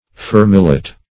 Search Result for " fermillet" : The Collaborative International Dictionary of English v.0.48: Fermillet \Fer"mil*let\, n. [OF., dim. of fermeil, fermail, clasp, prob. fr. OF.